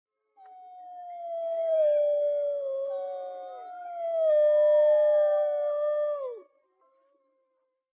Das Heulen eines Wolfes ist ein tiefes, langgezogenes Geräusch, das über große Entfernungen hinweg gehört werden kann, oft über mehrere Kilometer.
Wolf Heulen
Wolf-Heulen-2-u_ygwlkmquqg.mp3